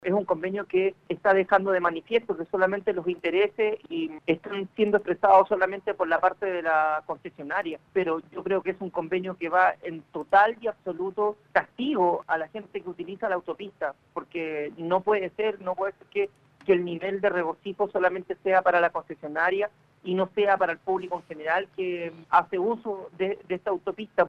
El jefe comunal de Hijuelas, José Saavedra, criticó las características de este acuerdo con la concesionaria de la autopista Los Andes:
Alcalde-de-Hijuelas-Jose-Saavedra-2.mp3